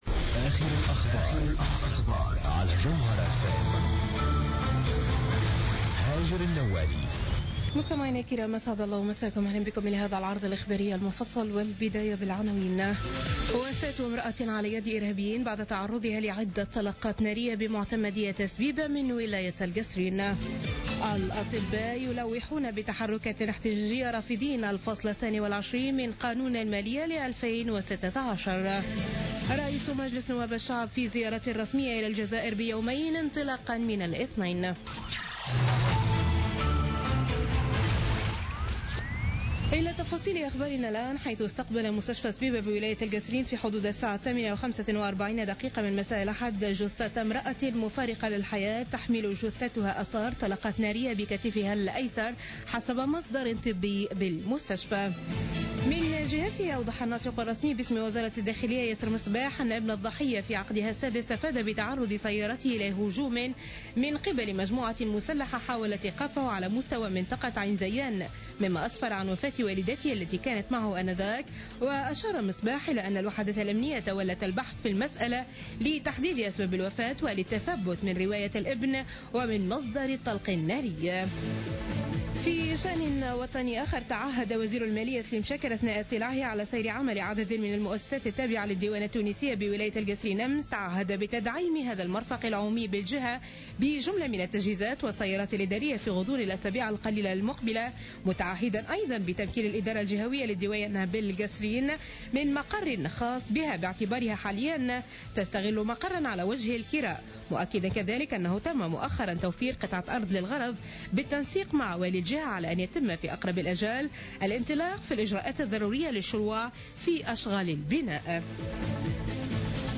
Journal Info 00h00 du lundi 4 Avril 2016